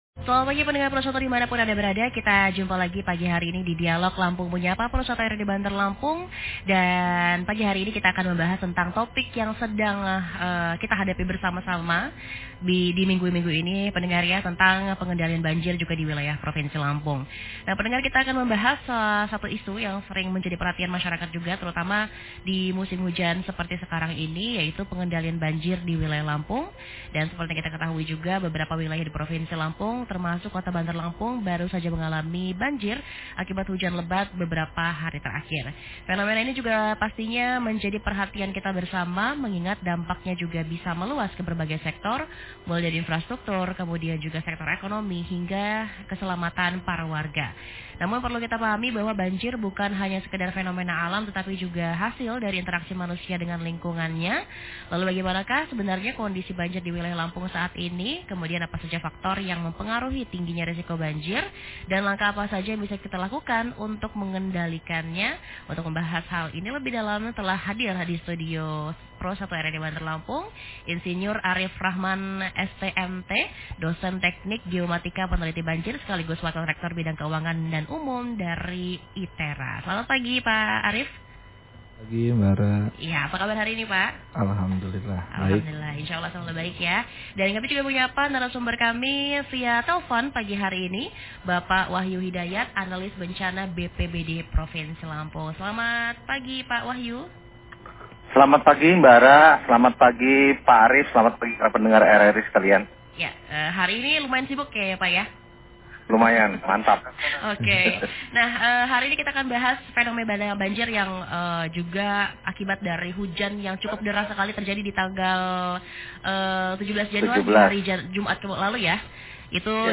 Dialog Lampung Menyapa Pro 1 RRI Bandar Lampung - 21 Januari 2025 | PPID LPP RRI